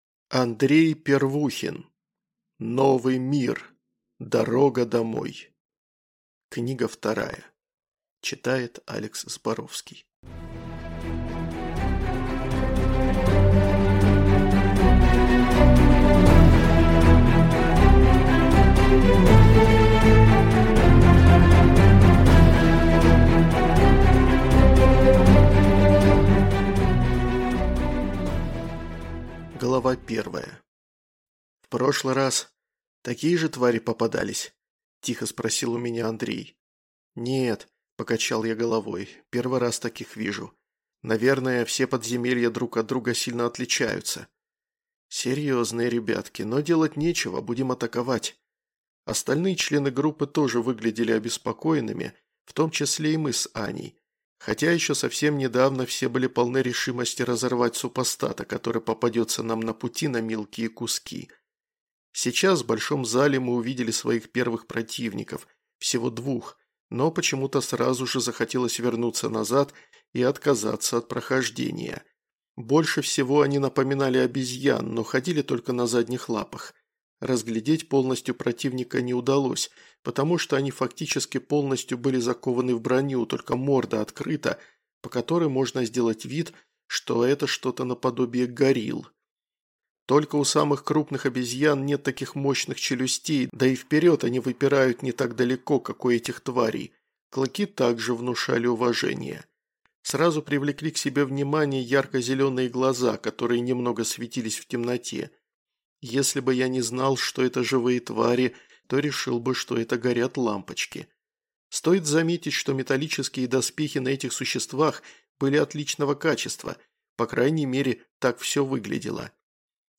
Слушать аудиокнигу Безвозвратность полностью